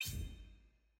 sfx_ui_map_vfx_resourceget.ogg